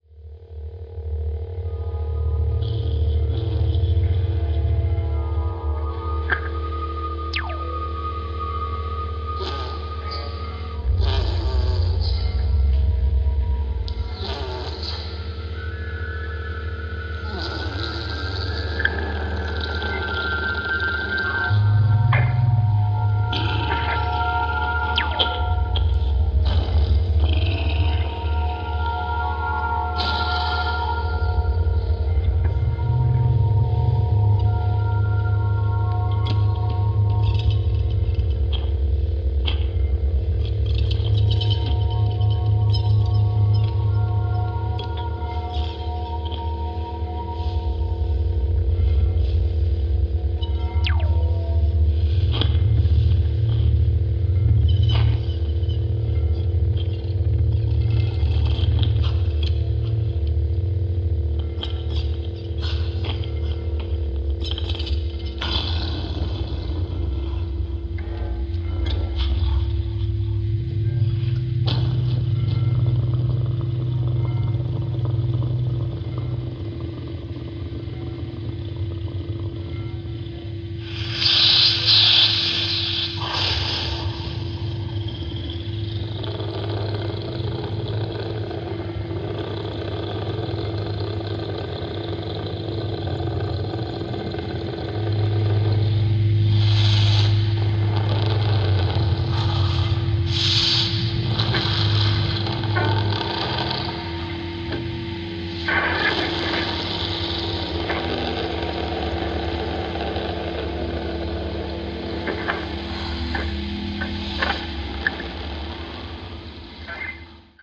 Melting cinematic sounds.